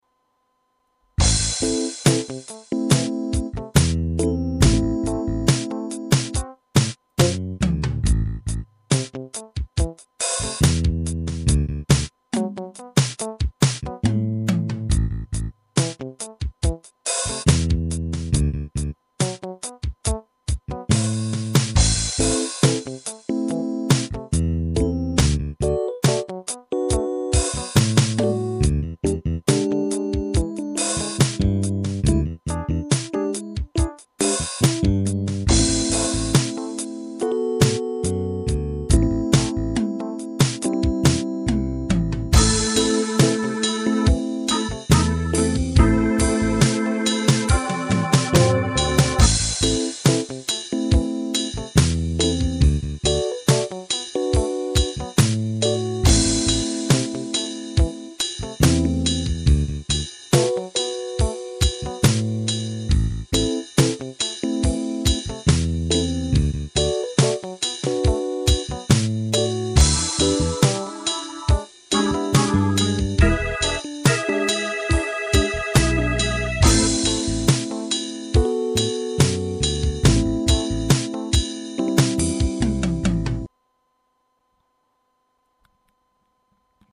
Chanting